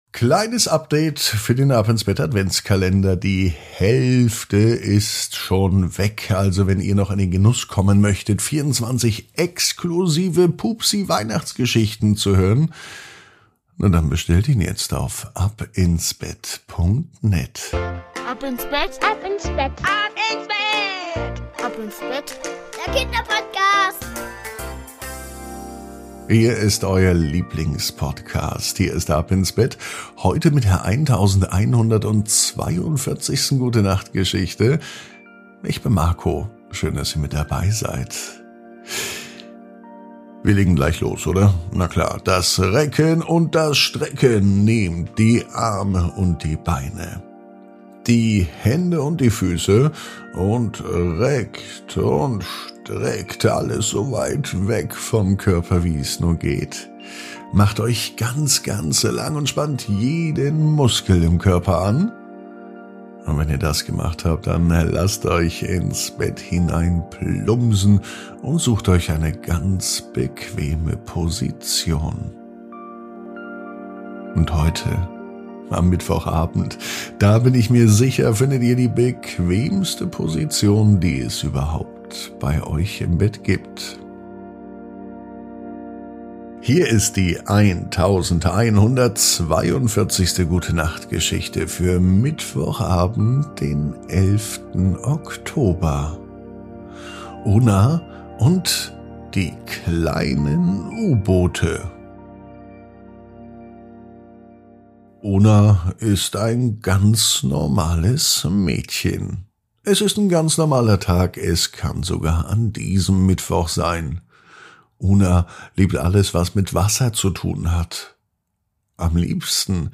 Die Gute Nacht Geschichte für Mittwoch